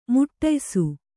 ♪ muṭṭaysu